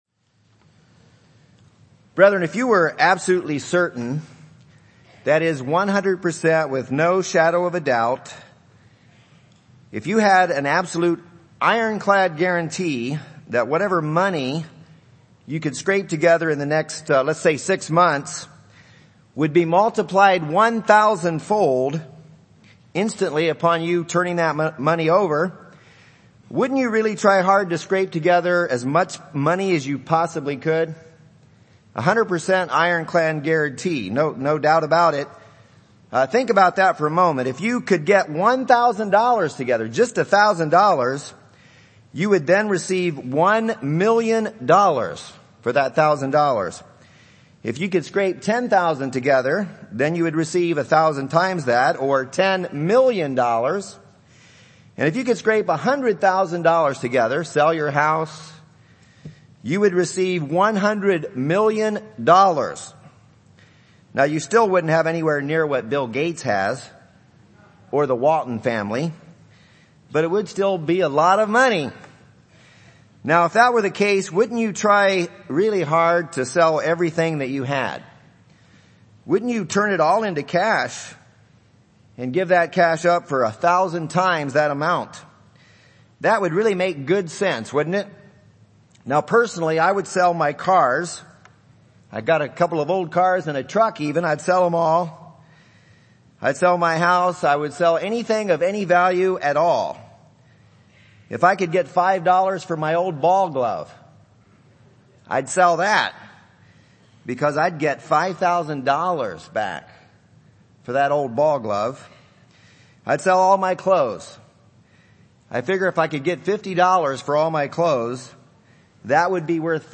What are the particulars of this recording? This sermon was given at the Wisconsin Dells, Wisconsin 2015 Feast site.